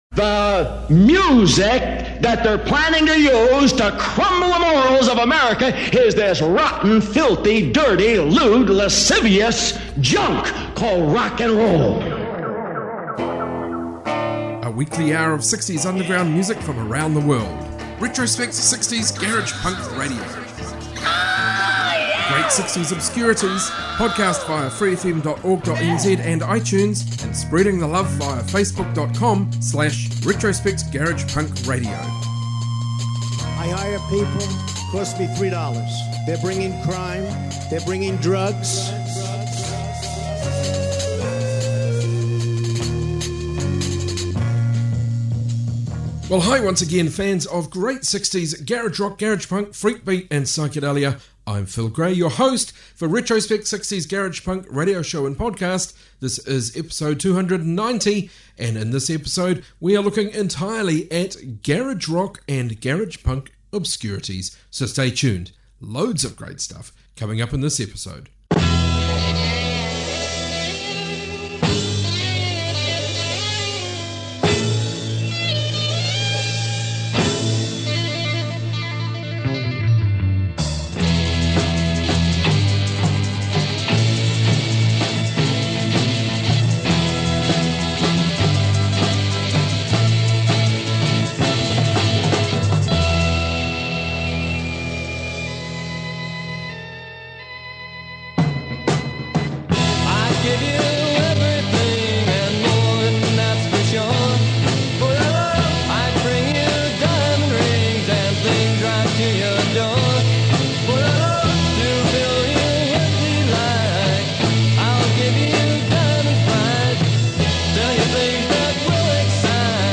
60s garage obscurities